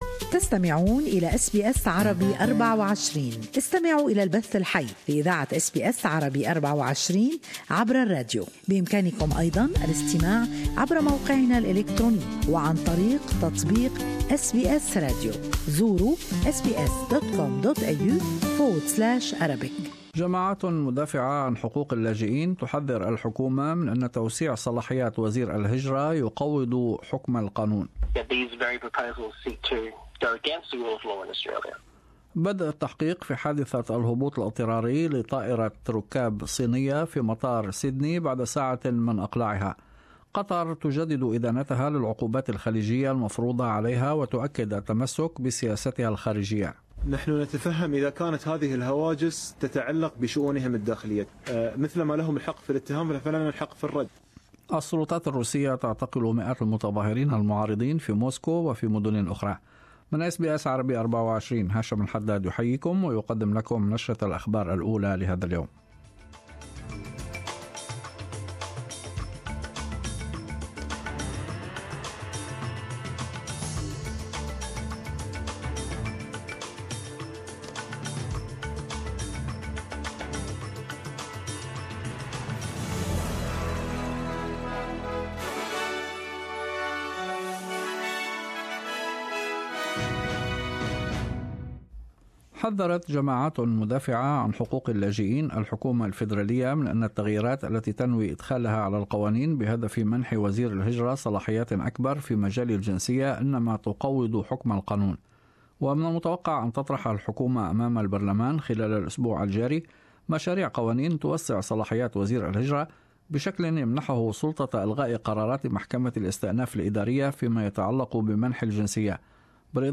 Morning news bulletin.